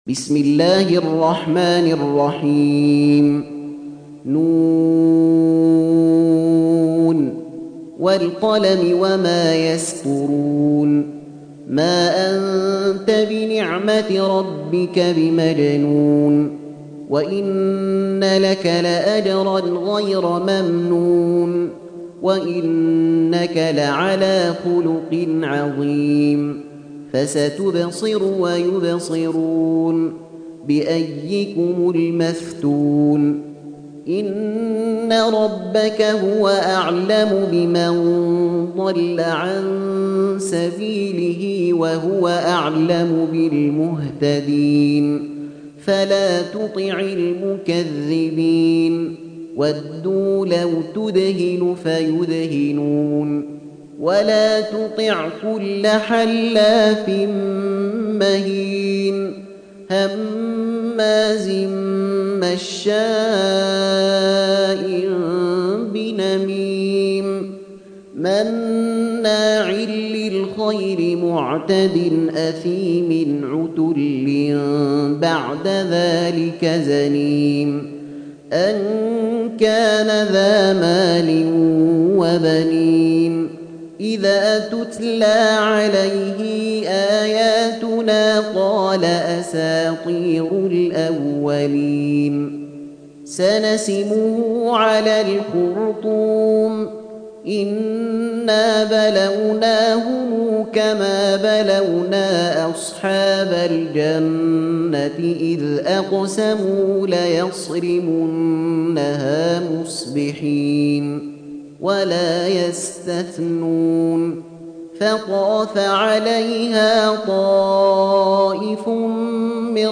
Surah Sequence تتابع السورة Download Surah حمّل السورة Reciting Murattalah Audio for 68. Surah Al-Qalam سورة القلم N.B *Surah Includes Al-Basmalah Reciters Sequents تتابع التلاوات Reciters Repeats تكرار التلاوات